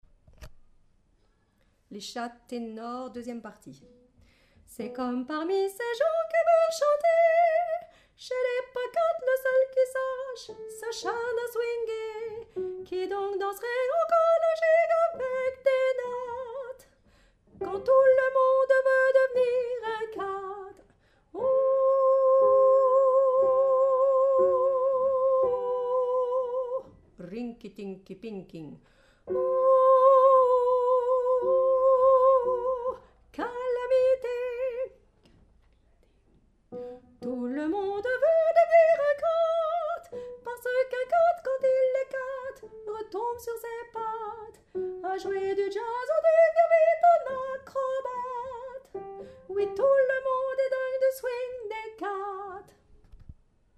Ténor
aristo2_Tenor.mp3